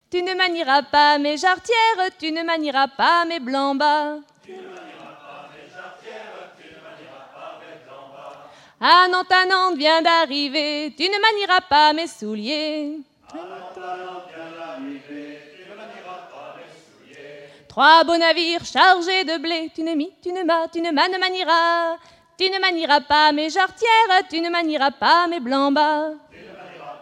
Genre laisse
Festival de la chanson traditionnelle - chanteurs des cantons de Vendée